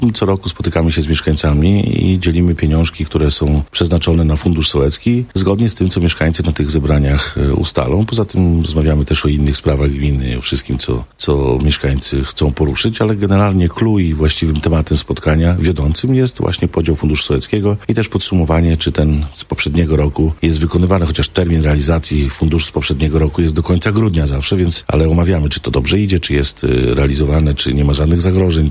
Powiedział wójt gminy Ełk Tomasz Osewski.